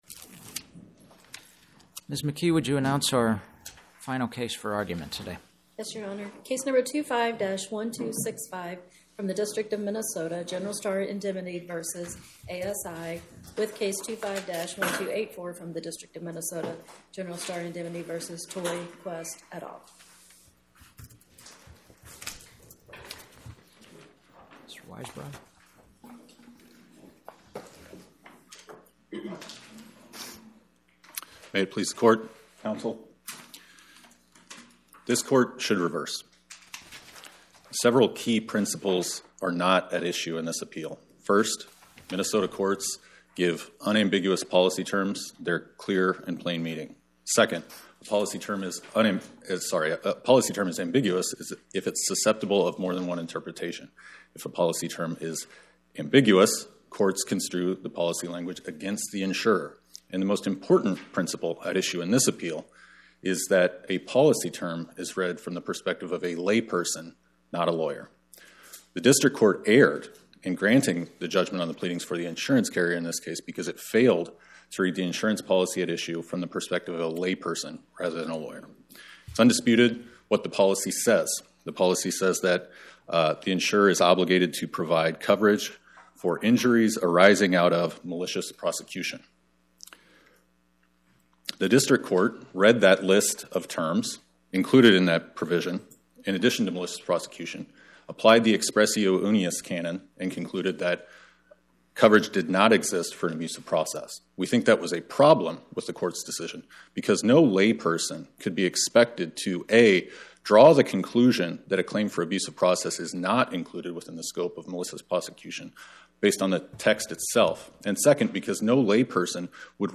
My Sentiment & Notes 25-1265: General Star Indemnity Company vs ASI, Inc. Podcast: Oral Arguments from the Eighth Circuit U.S. Court of Appeals Published On: Wed Oct 22 2025 Description: Oral argument argued before the Eighth Circuit U.S. Court of Appeals on or about 10/22/2025